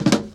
Roll (1).wav